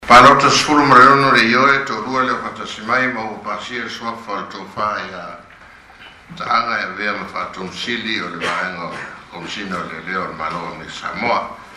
Senate President Tuaolo Manaua Fruean announcing the confirmation vote for High Talking Chief Taaga Saite Moliga to serve as Commissioner of Public Safety. The Senate confirmed Taaga by unanimous vote, 16-0, Wednesday morning.